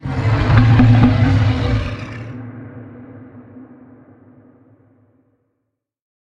File:Sfx creature bruteshark callout 02.ogg - Subnautica Wiki
Sfx_creature_bruteshark_callout_02.ogg